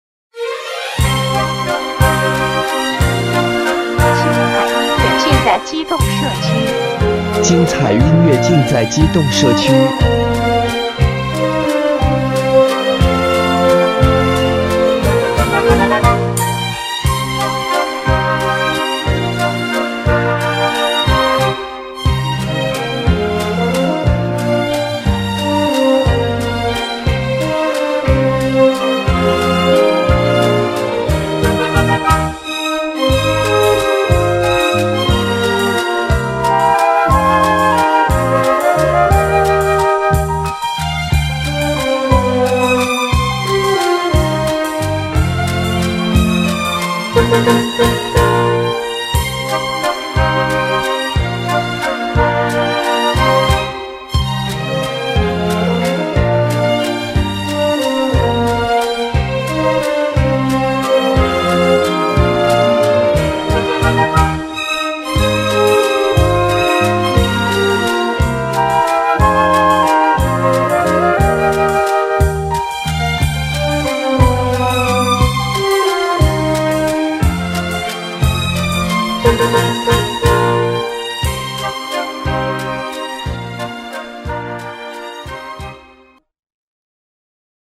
唱片风格：发烧乐器纯音乐(Instrumental Music)